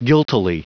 Prononciation du mot guiltily en anglais (fichier audio)
Prononciation du mot : guiltily